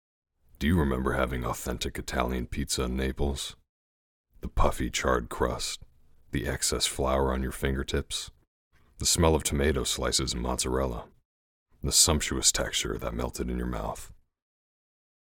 Travel Ad Demo
General American
Young Adult
Middle Aged